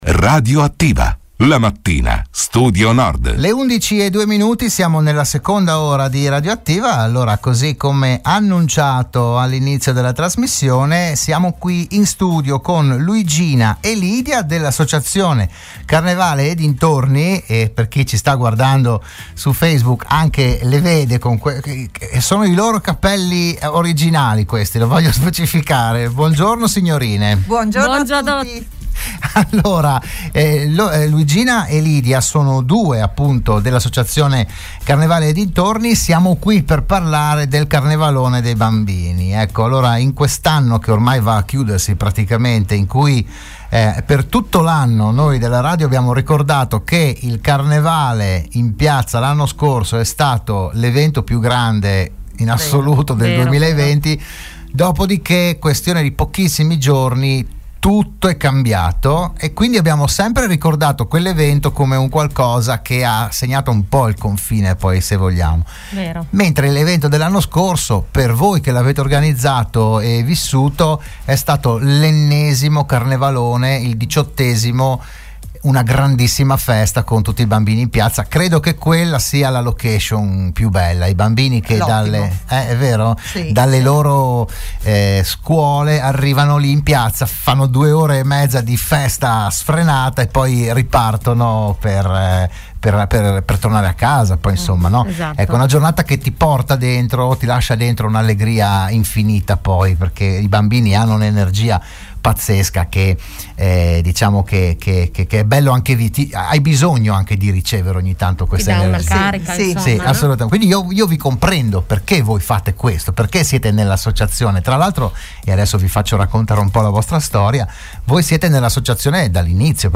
L'AUDIO e il VIDEO dell'intervento a Radio Studio Nord